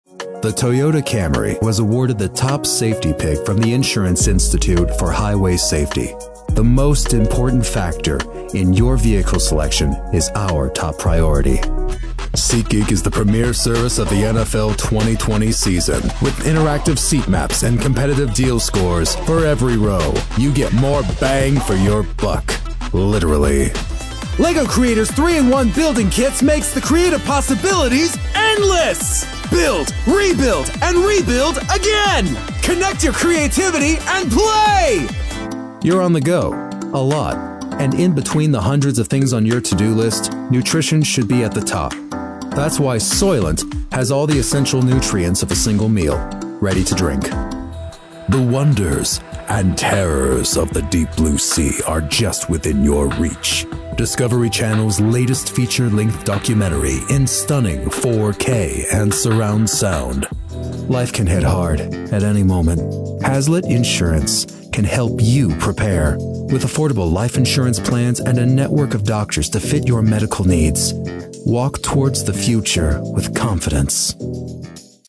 Announcer , Male , Mid-Range , Versatile